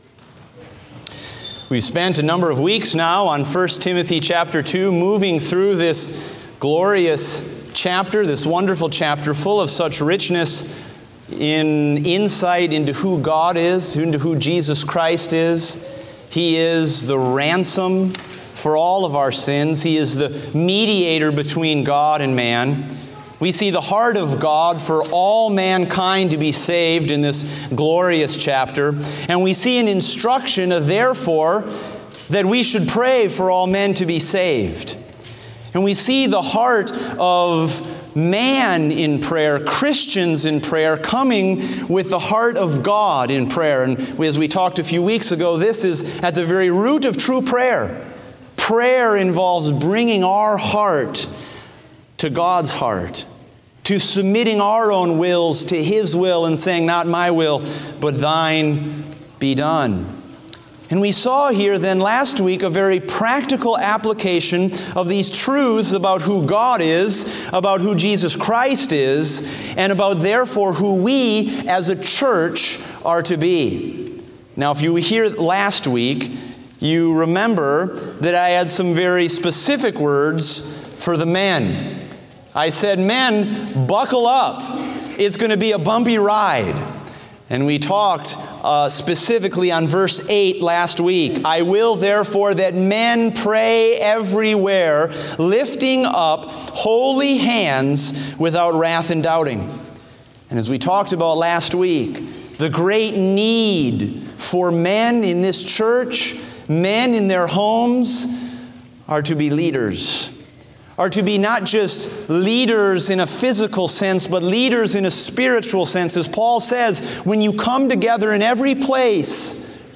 Date: October 9, 2016 (Morning Service)